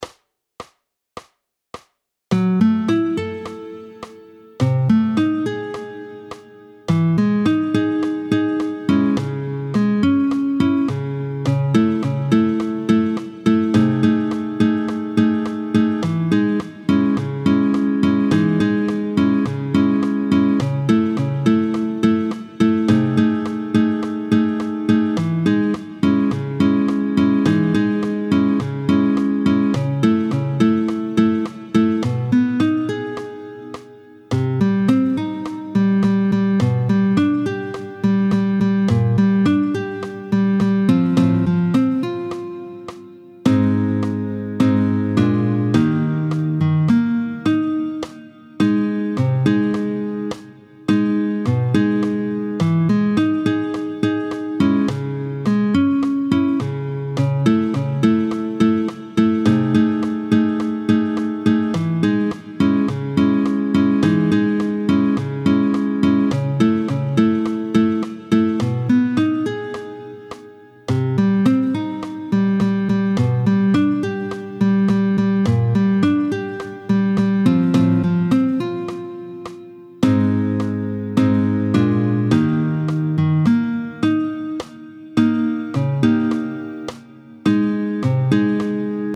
démo guitare